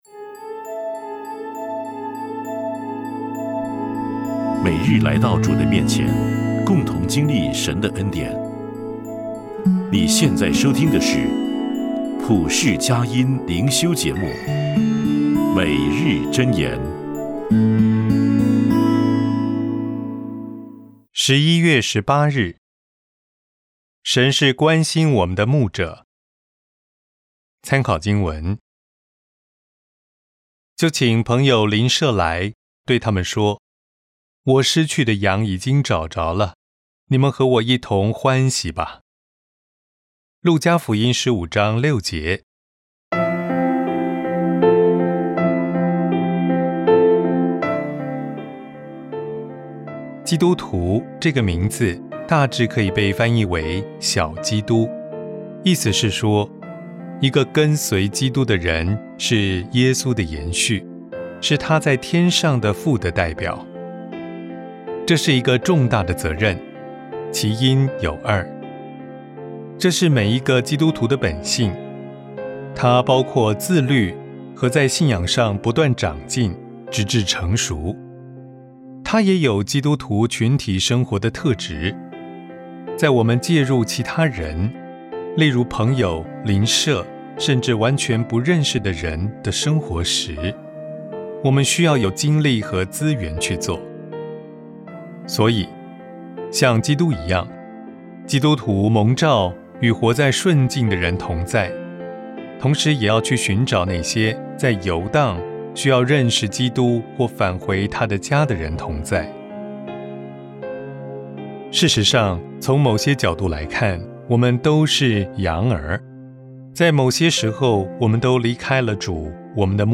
诵读